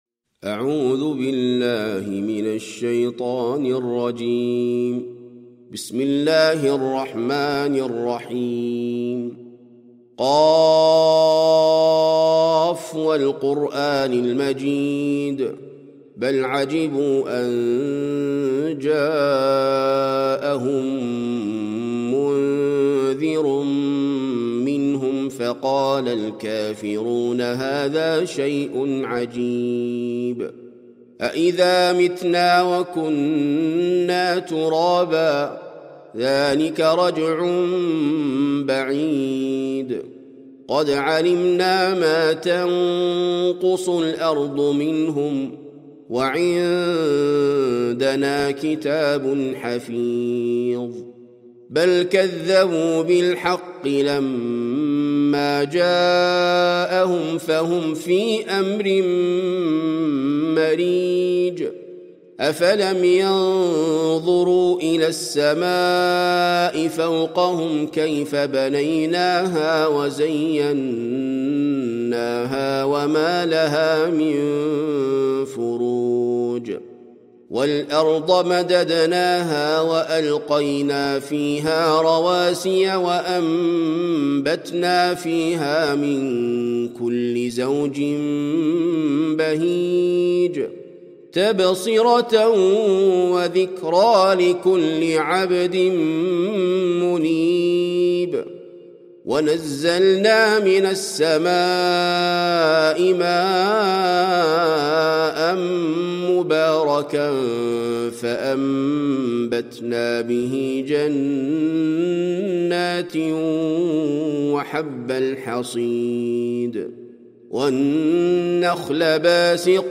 سورة ق - المصحف المرتل (برواية حفص عن عاصم)
جودة عالية